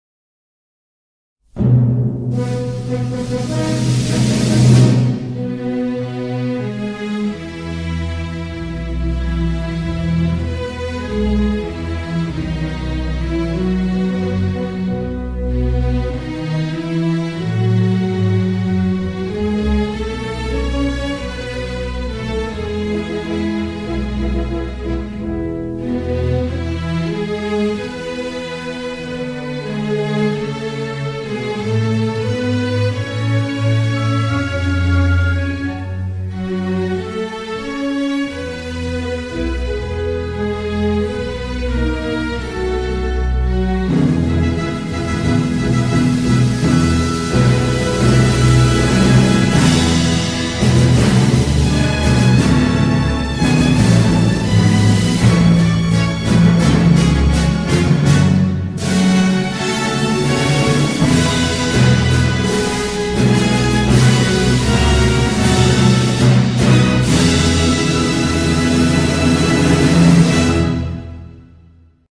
Оркестровая интерпретация